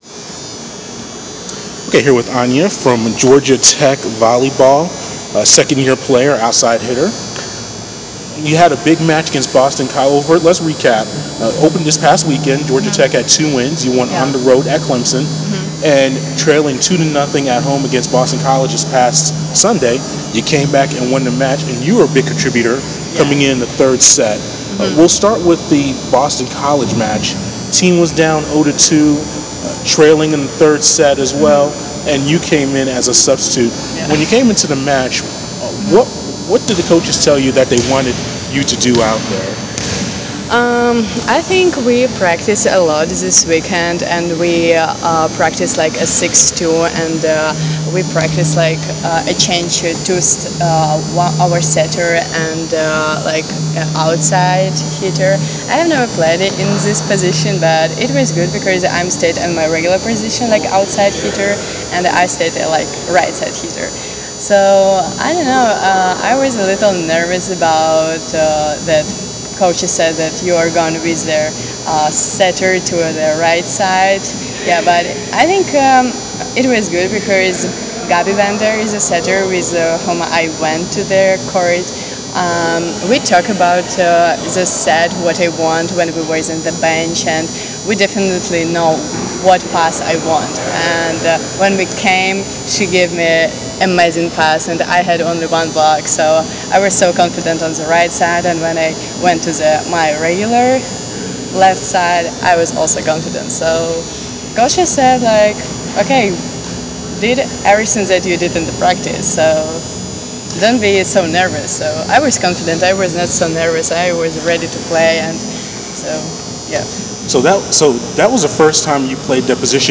Fighting Peaches: Interview